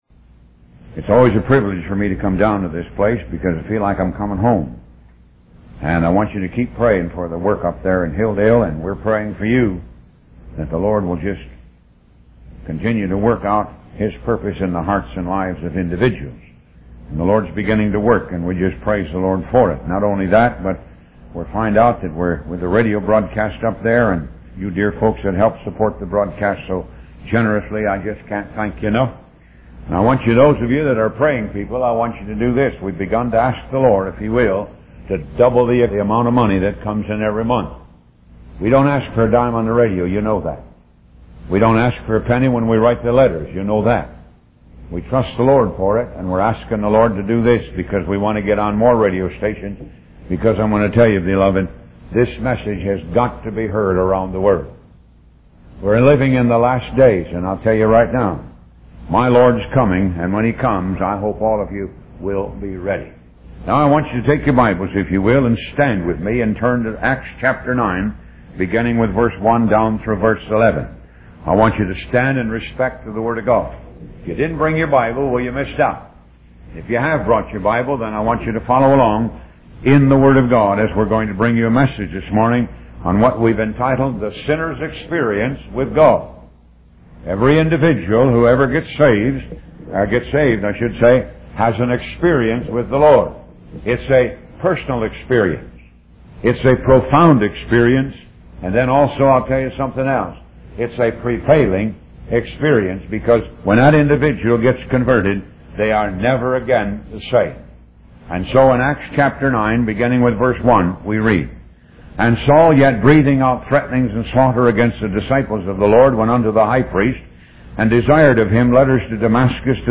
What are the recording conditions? Upon his passing, the Ministry has continued the radio broadcast on some radio stations and through various social media sites.